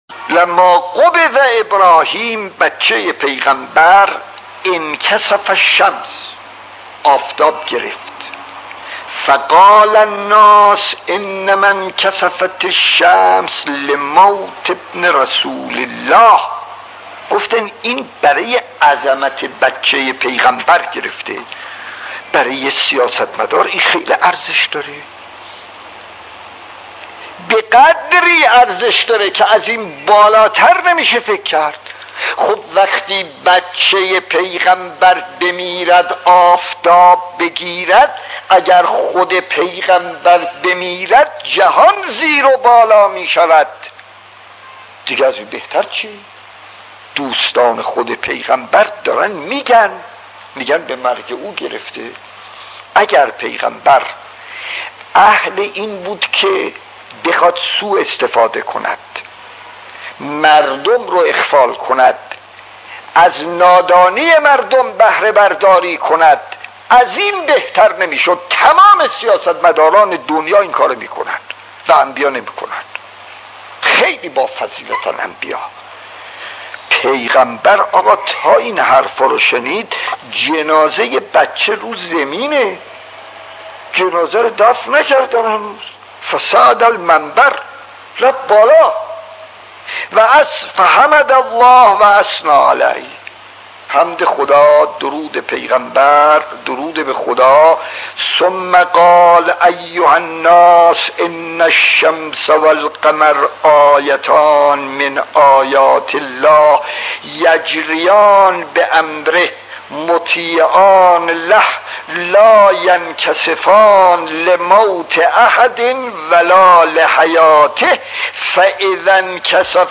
داستان 47 : وقوع کسوف هنگام تولد فرزند پیامبر خطیب: استاد فلسفی مدت زمان: 00:09:09